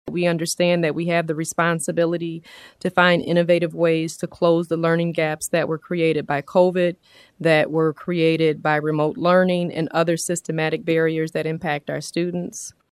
Harrison says these kinds of extra efforts are needed now more than ever.